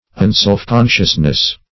Meaning of unselfconsciousness. unselfconsciousness synonyms, pronunciation, spelling and more from Free Dictionary.
unselfconsciousness.mp3